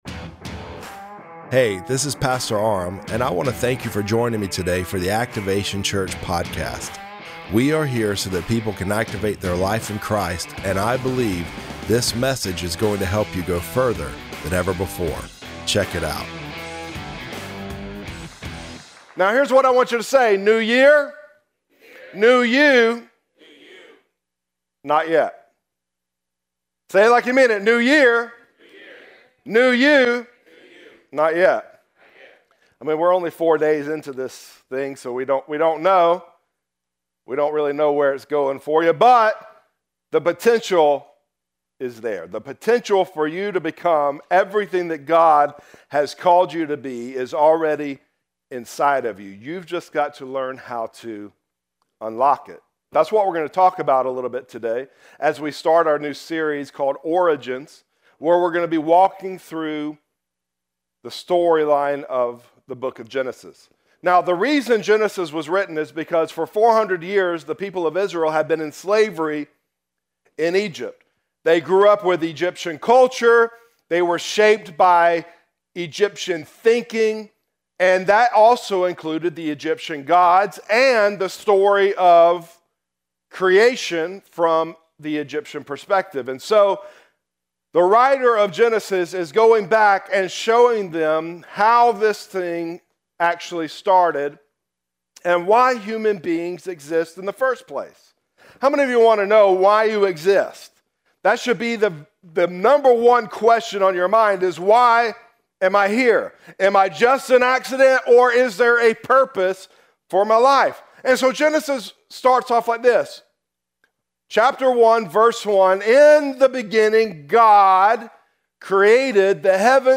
Sermons
Listen to Sunday sermons from Activation Church!